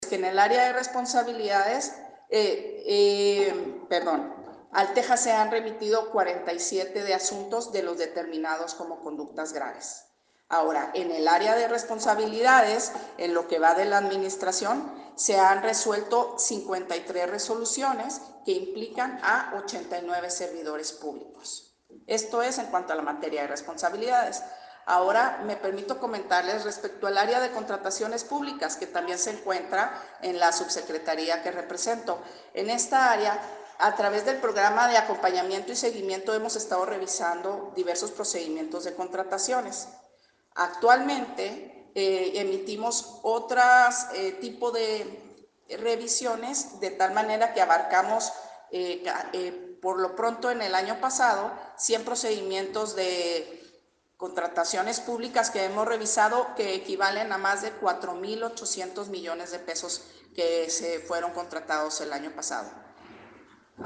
AUDIO: FLAVIA QUIÑONES CHÁVEZ, TITULAR DE LA SUBSECRETARÍA DE ASUNTOS JURÍDICOS, CONTRATACIONES PÚBLICAS Y RESPONSABILIDADES DE LA SECRETARÍA DE LA FUNCIÓN PÚBLICA (SFP)